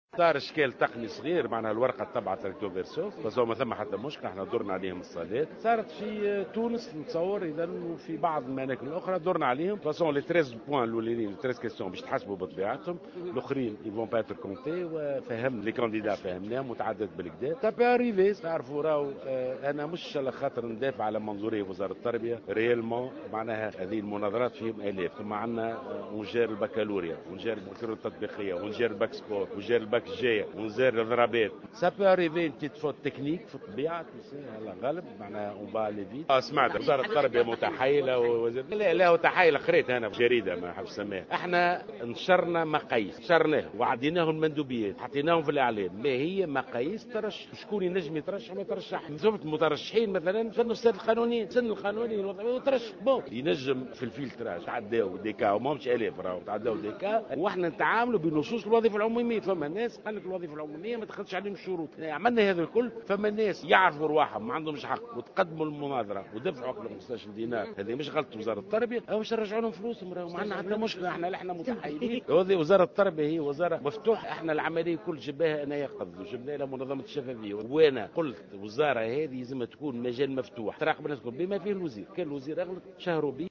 وأكد جلول في تصريح إعلامي اليوم الأحد أنه سيتم اعتماد الأسئلة الـ13 الأولى في الإصلاح وعدم أخذ بقية الأسئلة بعين الاعتبار.